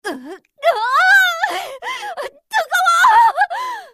slayer_f_voc_skill_rage1.mp3